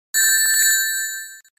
sonic-ring-sound2.mp3